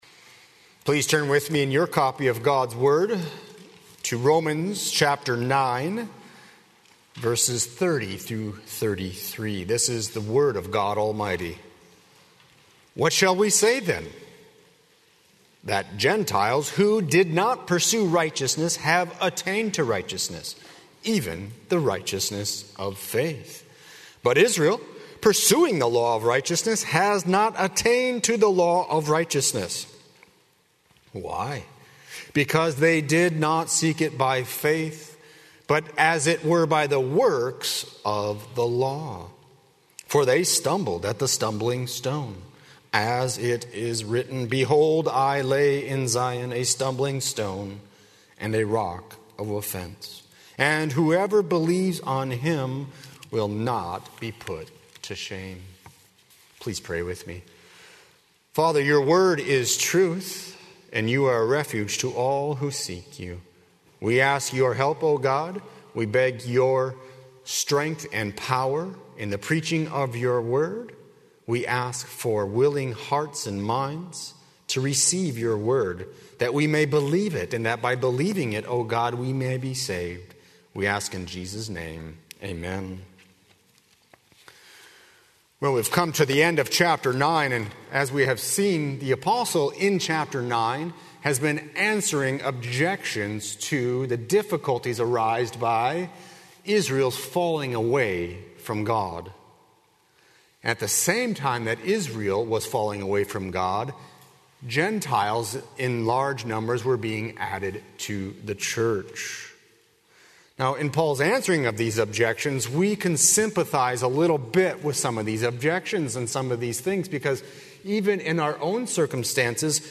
00:00 Download Copy link Sermon Text Romans 9:30–33